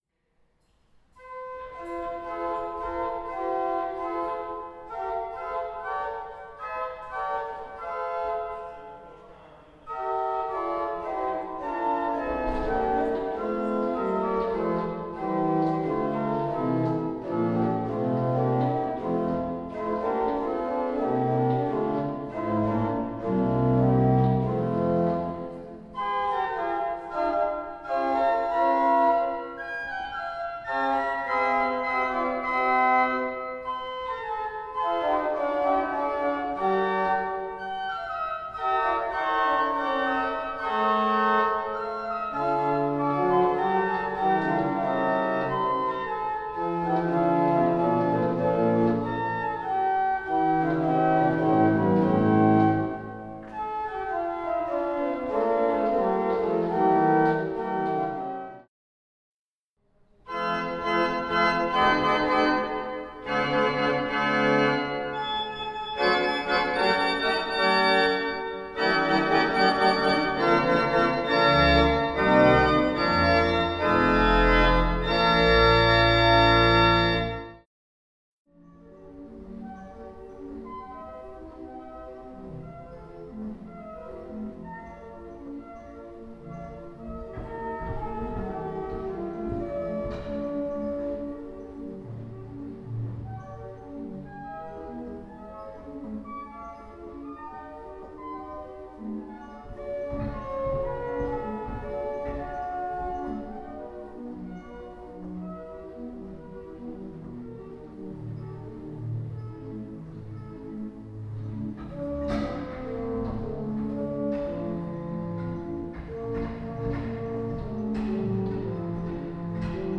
2 manuály 14 rejstříků
Romantické Pneumatická traktura
Zvuková ukázka varhan (2025)
svebohov-predstaveni-varhan.mp3